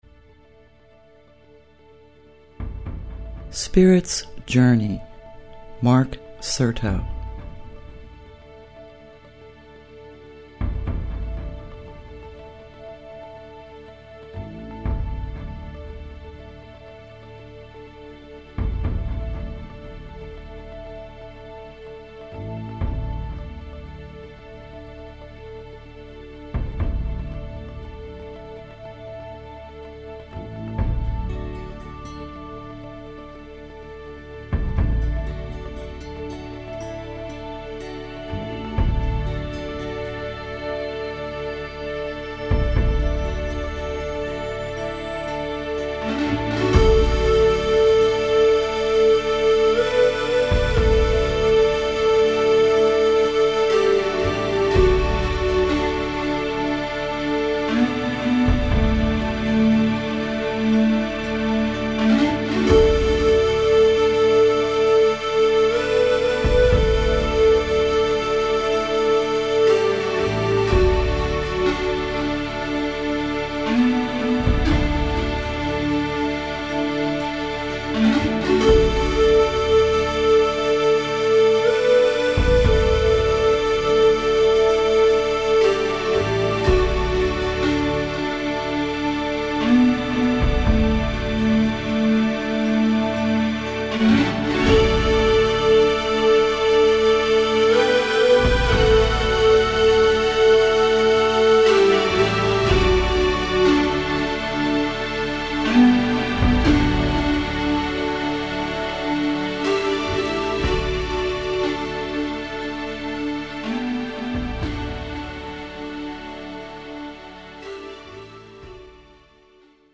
Muzica inspiratoare